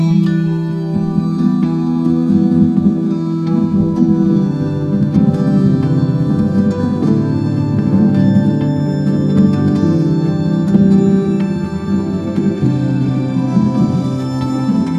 Generate Ancient Greek Lyre Instrumentals